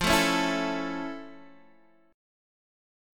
F+M7 chord